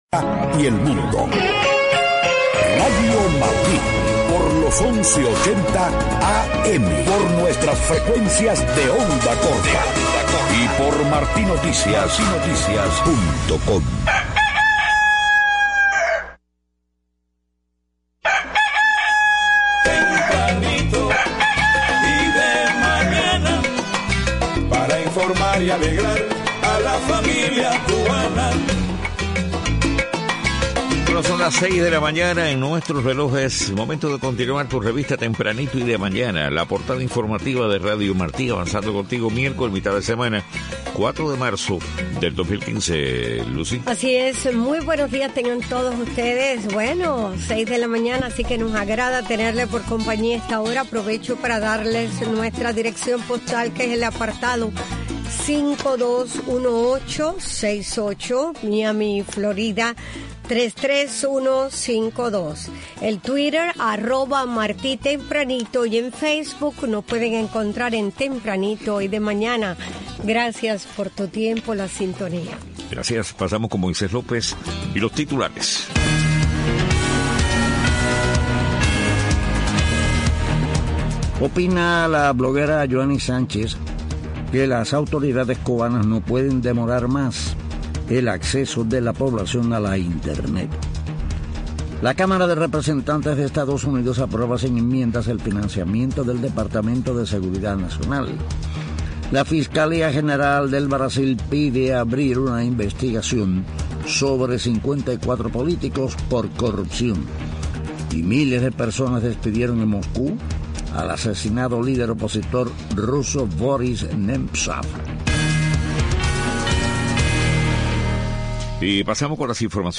6:00 a.m. Noticias: Bloguera Yoani Sánchez opina que las autoridades cubanas no pueden demorar más el acceso de la población a Internet. Cámara de Representantes de EEUU aprueba sin enmiendas el financiamiento del Departamento de Seguridad Nacional.